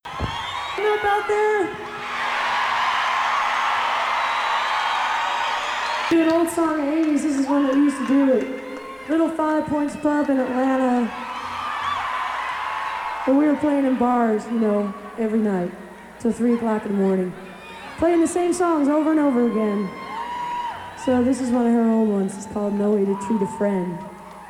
lifeblood: bootlegs: 1991-06-21: red rocks - denver, colorado (alternate version)
11. talk (0:24)